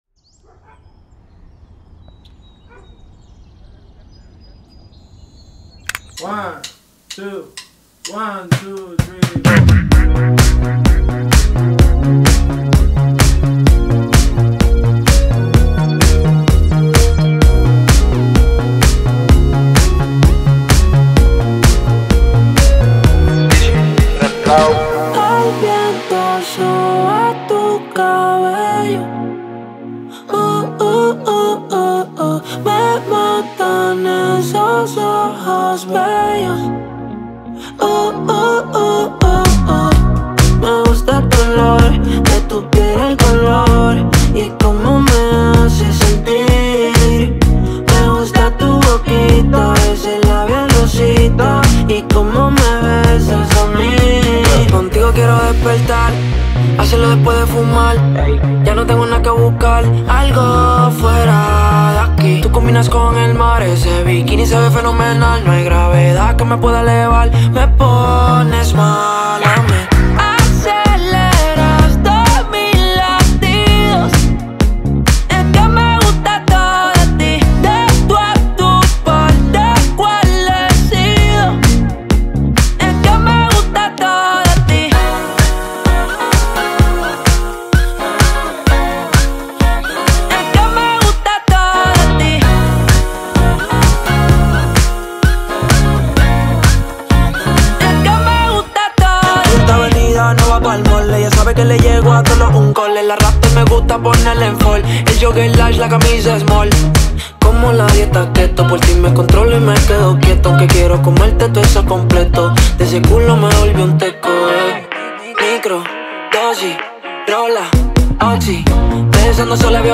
موزیک‌های ریتمیک مخصوص ریلز